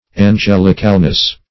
Search Result for " angelicalness" : The Collaborative International Dictionary of English v.0.48: Angelicalness \An*gel"ic*al*ness\, n. The quality of being angelic; excellence more than human.